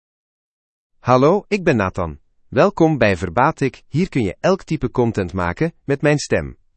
Nathan — Male Dutch (Belgium) AI Voice | TTS, Voice Cloning & Video | Verbatik AI
Nathan is a male AI voice for Dutch (Belgium).
Voice sample
Male
Nathan delivers clear pronunciation with authentic Belgium Dutch intonation, making your content sound professionally produced.